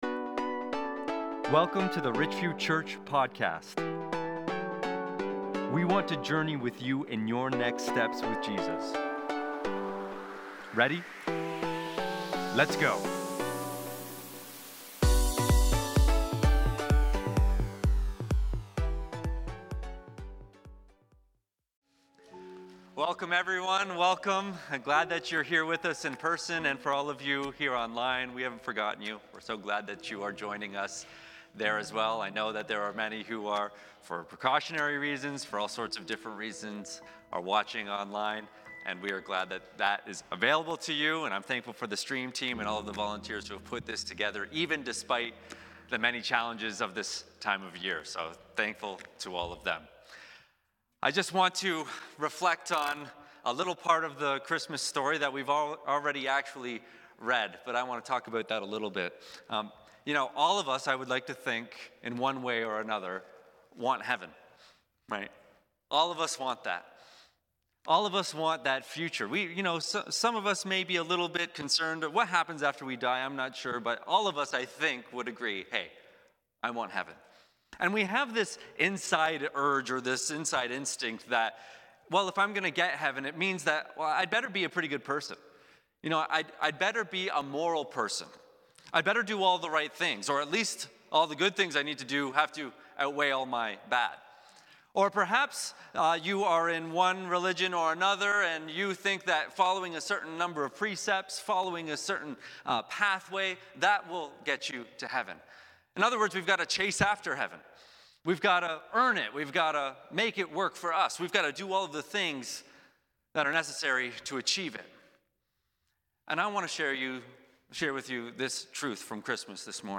Christmas Eve Candlelight Service